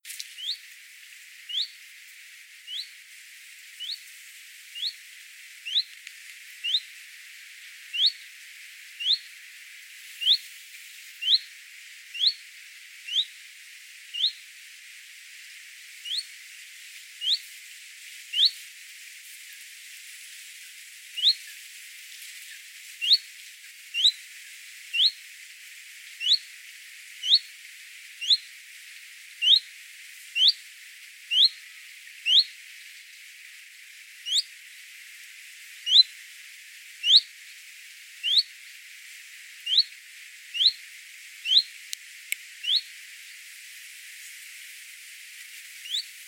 Zilpzalp Phylloscopus collybita Common Chiffchaff
Öschlesee OA, 20.09.2012 47 s Rufe